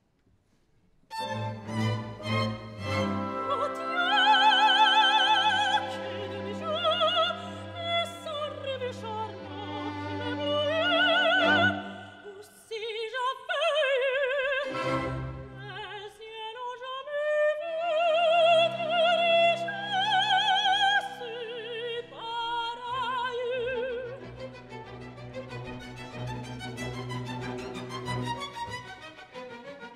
Cette soirée lyrique est placée sous le signe des amours dans toutes leurs définitions !
Soprano
Ténor
Basse
Extraits d’opéras de BIZET, DONIZETTI, GOUNOD, MOZART, PUCCINI, VERDI
Power-airs-dopera-Luxembourg.wav